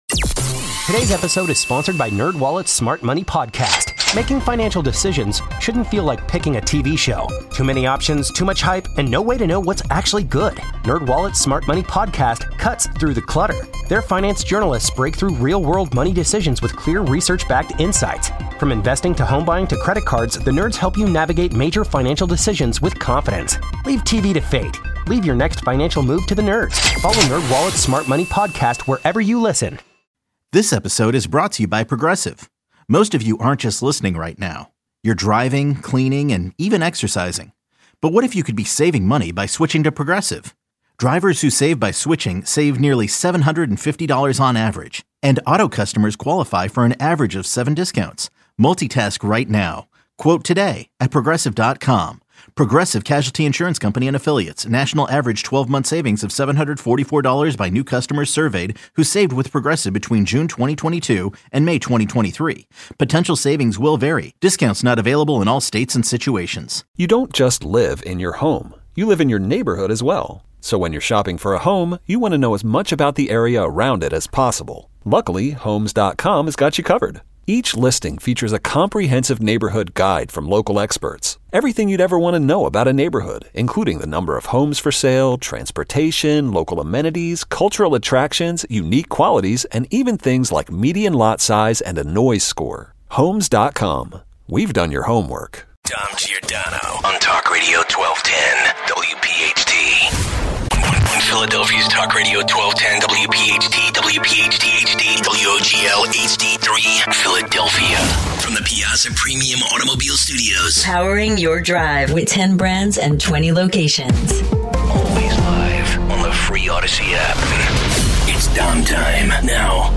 225 - Jeff Bezos wants an editorial board for the Washington Post, is that fair or foul? 240 - Your calls. 250